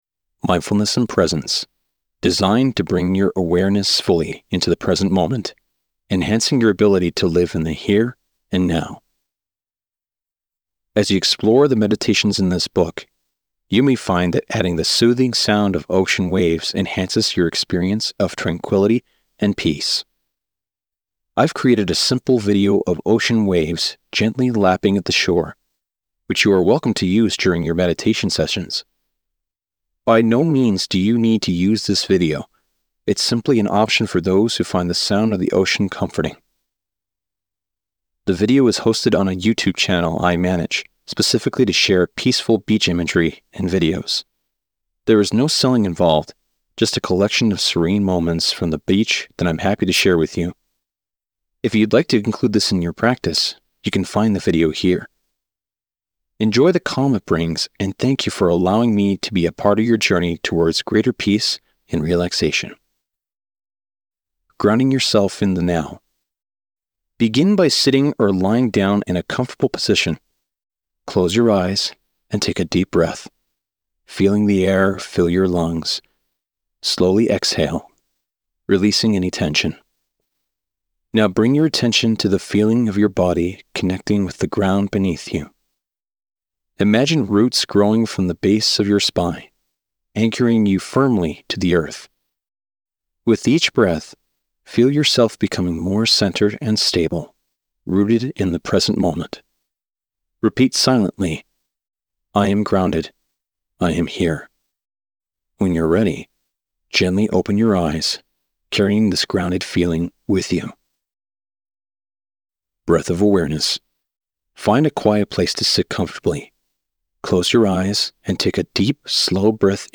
Hire Professional Male Voice Over Talent, Actors & Artists Online
0319Meditation.mp3